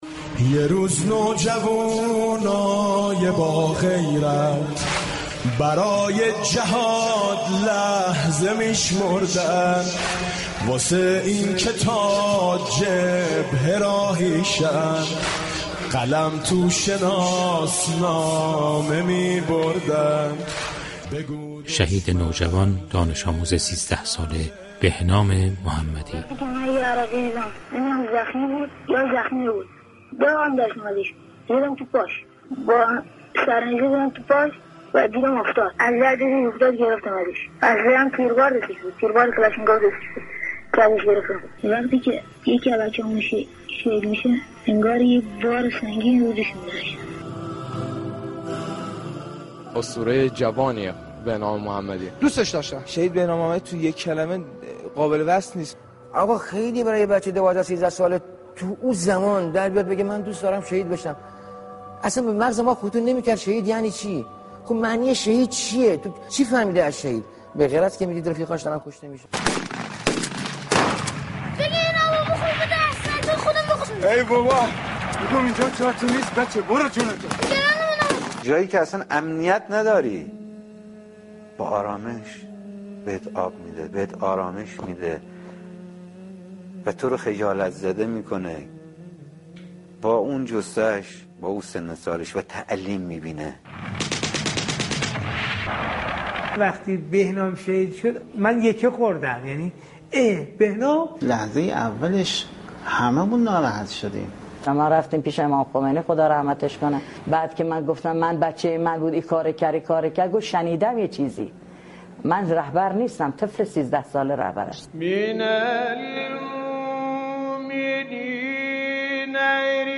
این ویژه‌برنامه با هدف تبیین الگوگیری نسل امروز از دانش‌آموزان شهید، همراه با فضای حماسی و صمیمی روایت‌ها، در رادیو قرآن پخش و مورد توجه شنوندگان قرار گرفت.